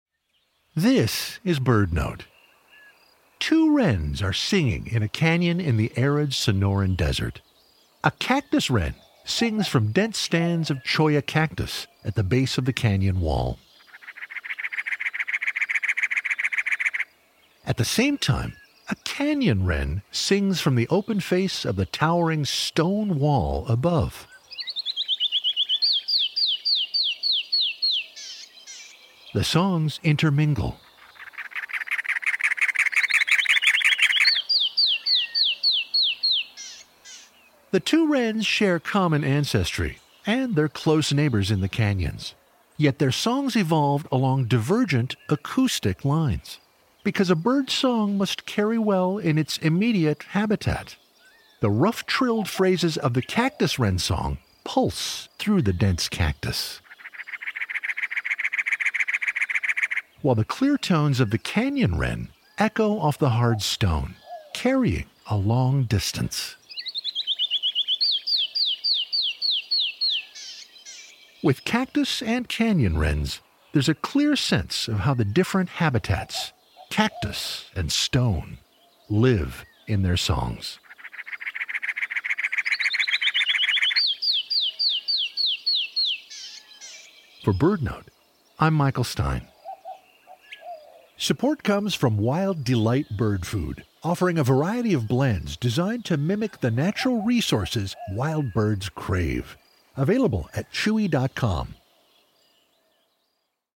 The Canyon Wren and Cactus Wren share common ancestry — and they’re close neighbors in the desert southwest. Yet their songs evolved along divergent acoustic lines.